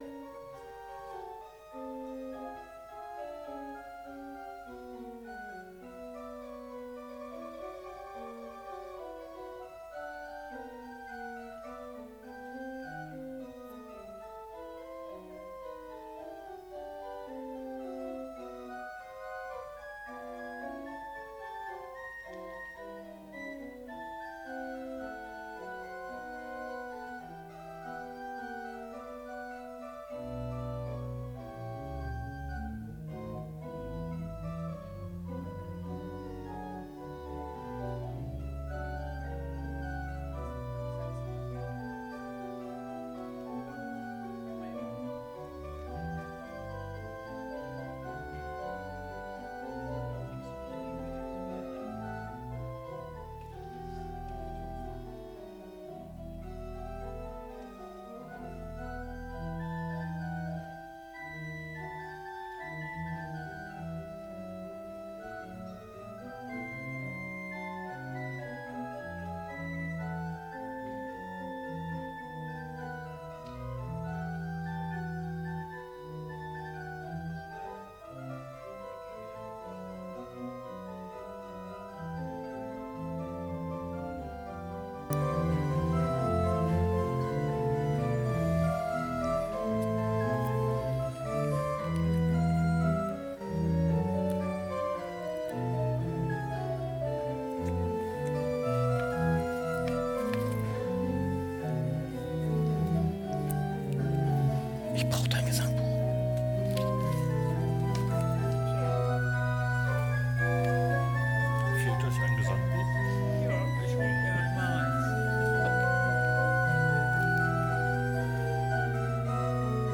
Gottesdienst - 27.07.2025 ~ Peter und Paul Gottesdienst-Podcast Podcast